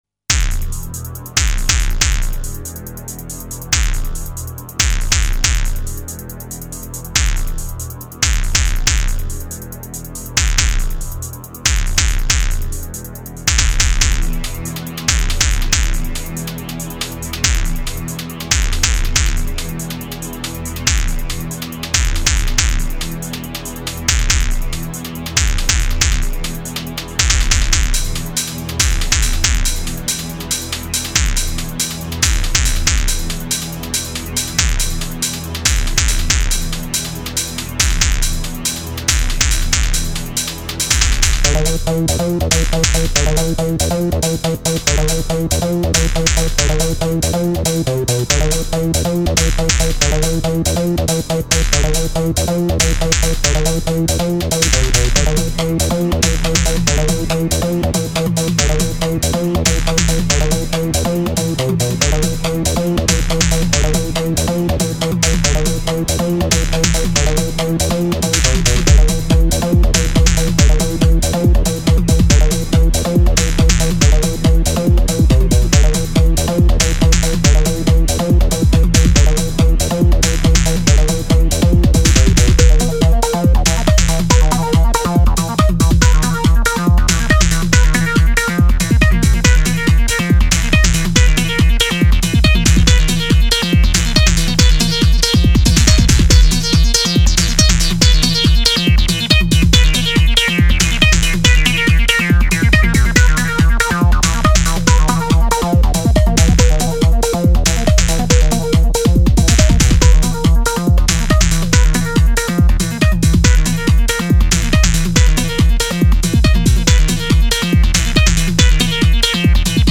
dance/electronic
Walking through the woods at night... eeire, right?
House
Techno
Drum & bass